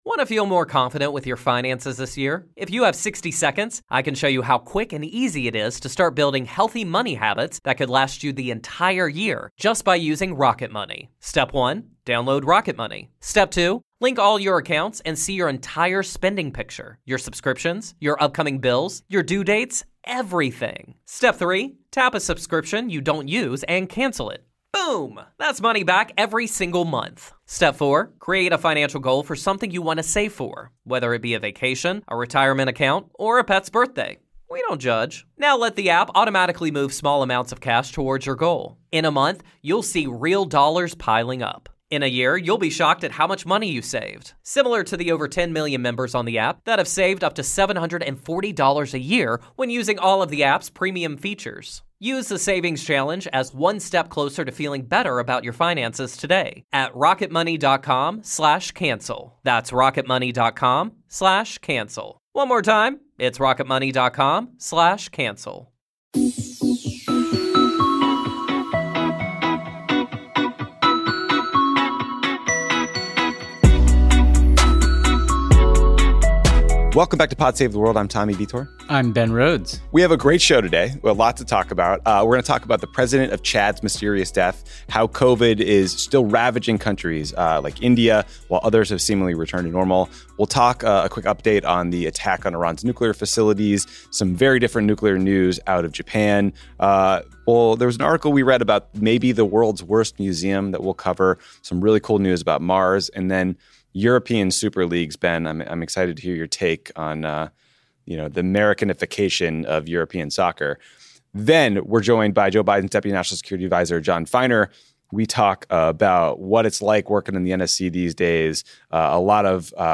Tommy and Ben discuss the president of Chad’s mysterious death, Vladimir Putin's imprisonment of Alexei Navalny, the devastating coronavirus outbreak in India, updates on what actually happened at the Natanz nuclear facility, SPACE HELICOPTERS!!, a not so super idea for a soccer league and more. Then Deputy National Security Advisor Jon Finer joins Tommy for a conversation on the Biden administration's Russia policy and the decision to withdraw from Afghanistan.